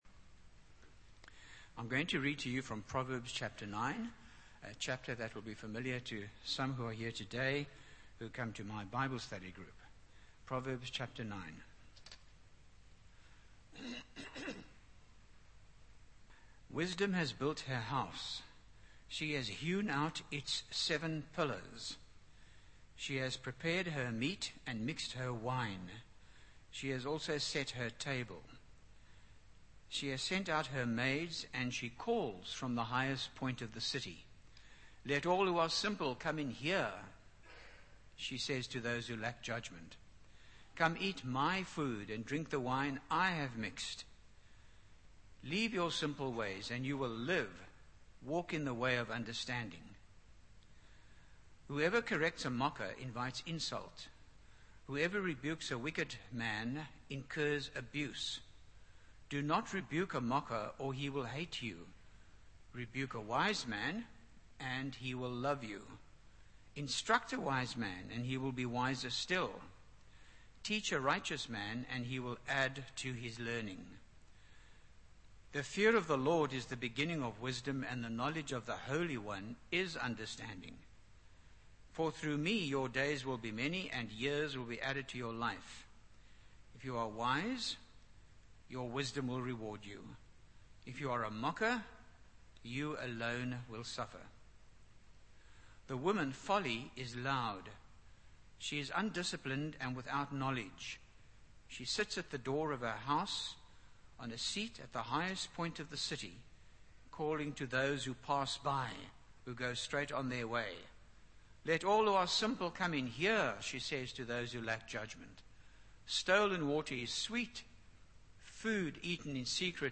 by Frank Retief | Jan 21, 2025 | Frank's Sermons (St James) | 0 comments